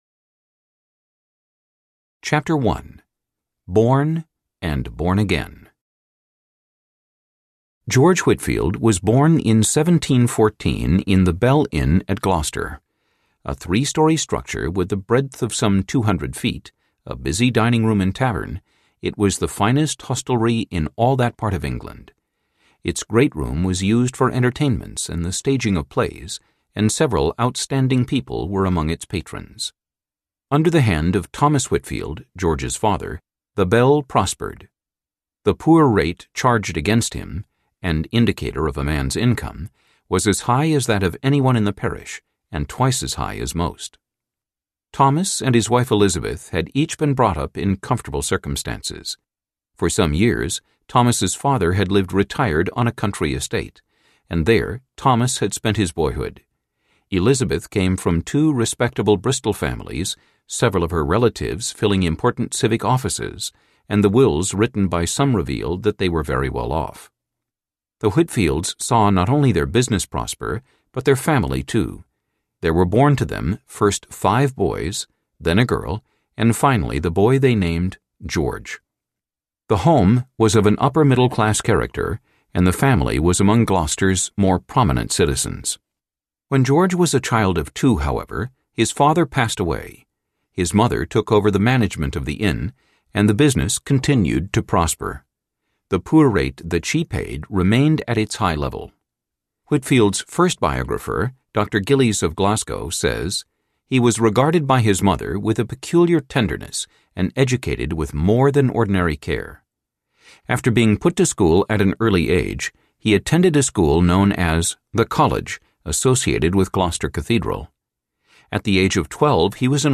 George Whitefield Audiobook
Narrator
5.9 Hrs. – Unabridged